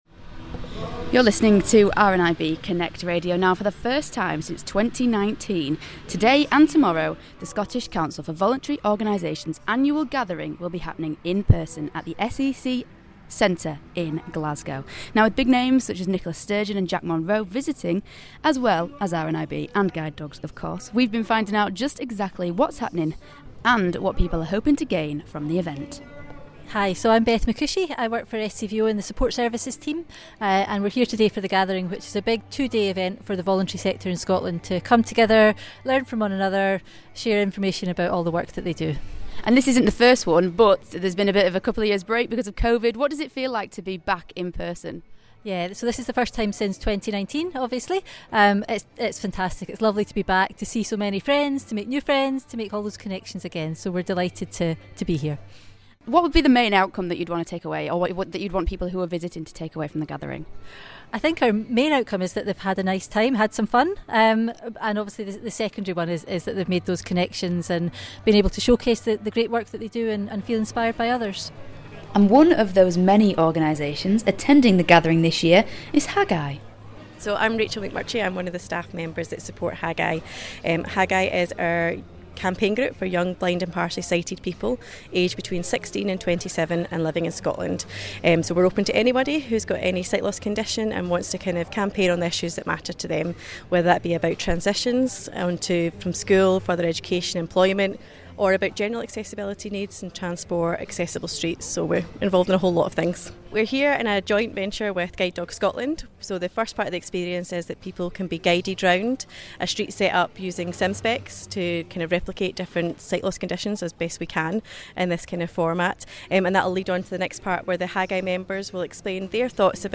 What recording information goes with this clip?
For the first time since 2019, the Scottish Council of Voulentry Organisations annual Gathering is happening in person!